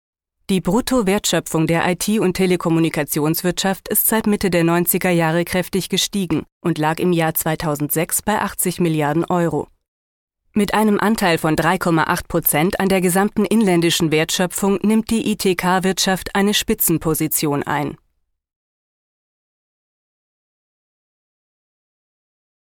Sprecherin aus Berlin mit vielseitig einsetzbarer Stimme - frisch/jugendlich - sachlich/seriös - sanft & warm.
Sprechprobe: eLearning (Muttersprache):